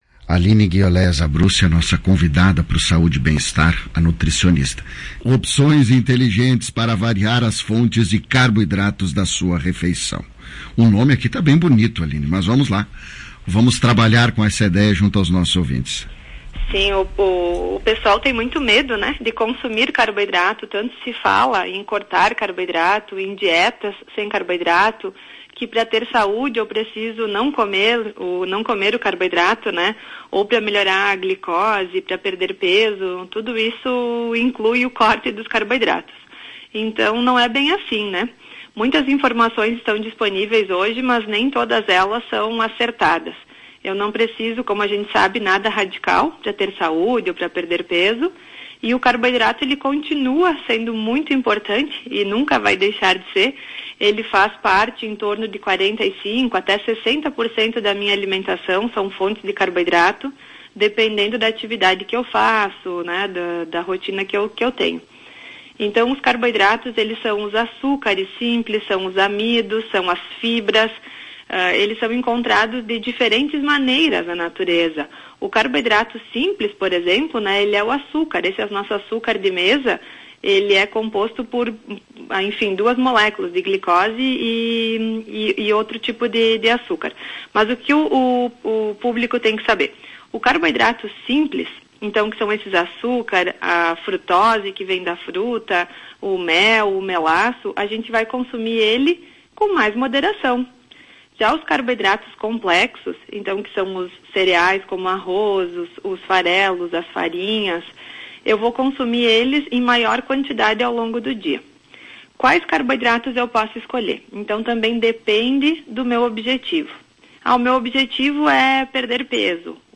Você sabe quais são as opções inteligentes para variar as fontes de carboidrato da sua refeição diária? Em um bate papo